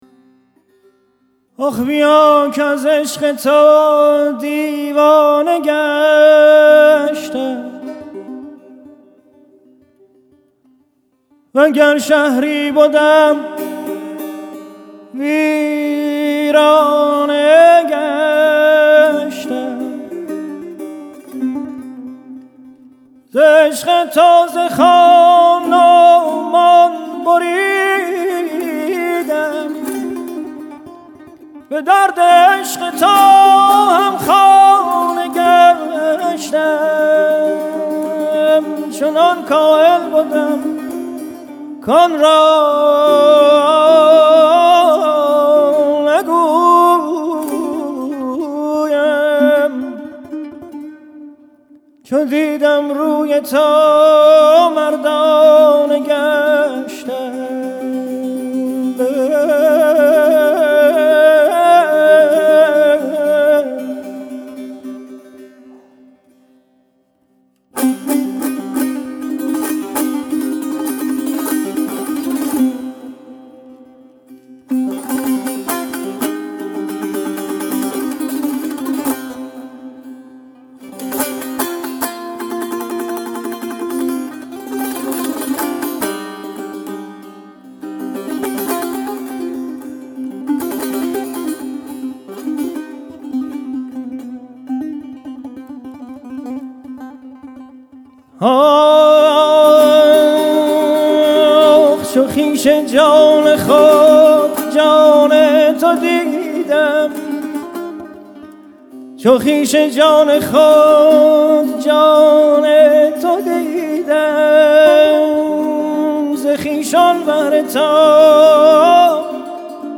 2. 2 Avaz